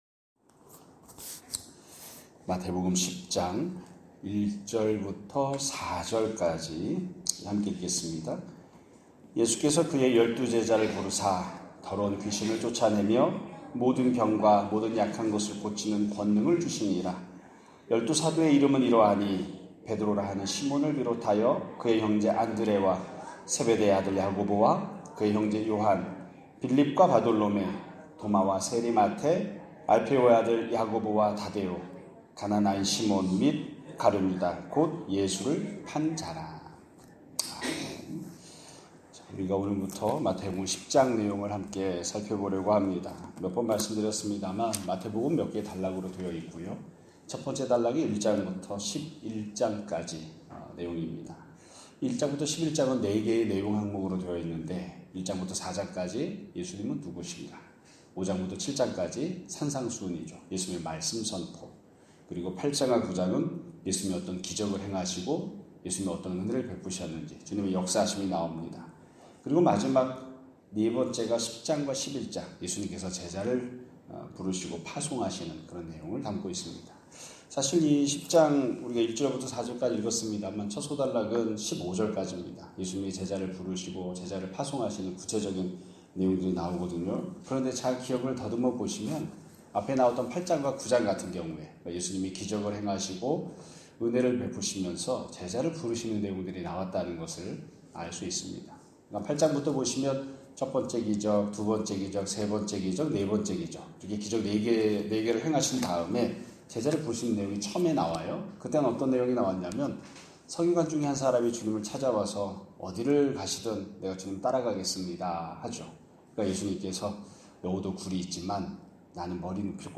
2025년 8월 5일 (화요일) <아침예배> 설교입니다.